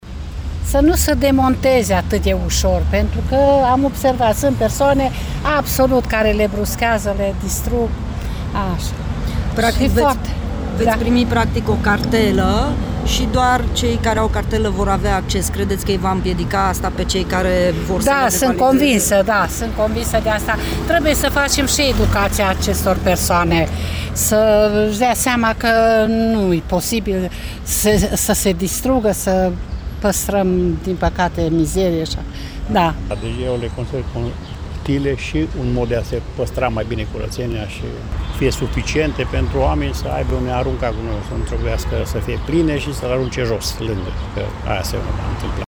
Locuitorii din zona străzii Bucinului, unde au fost deja instalate insulele ecologice, s-au declarat încântaţi de proiect: